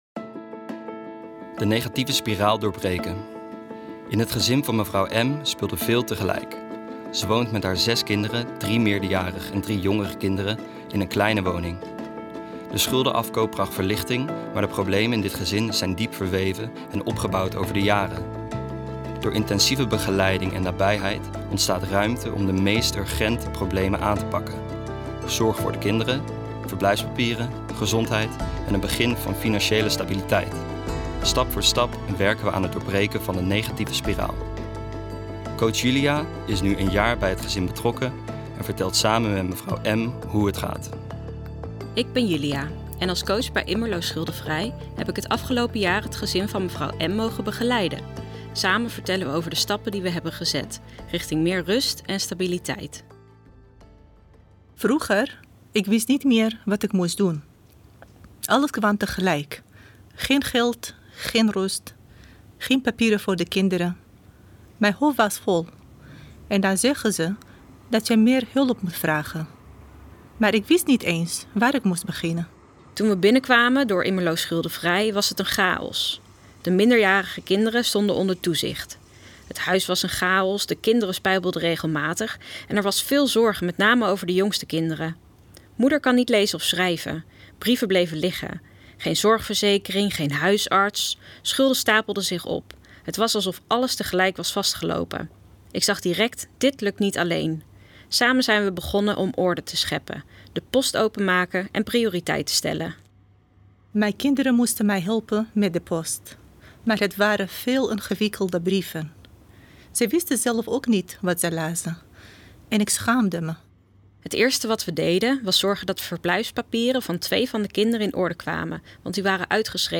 De verhalen worden verteld door stemacteurs.